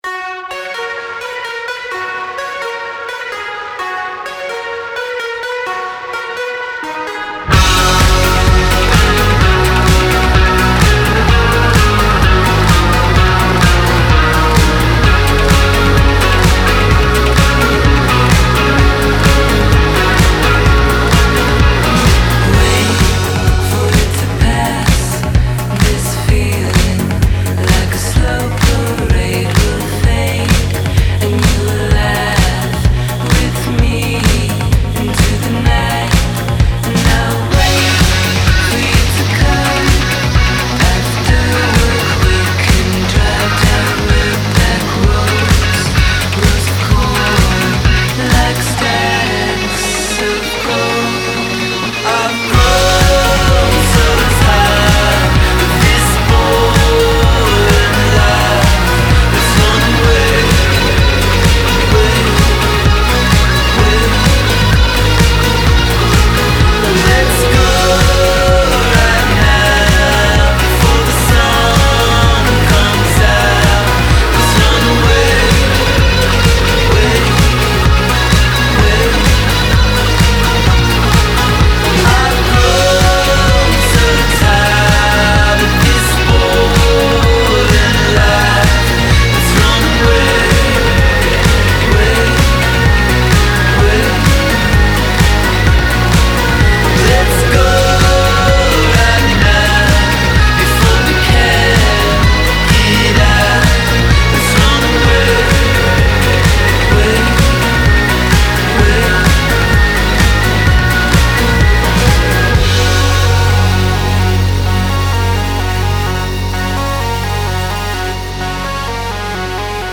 BPM128
Audio QualityPerfect (High Quality)
an indie pop band from Omaha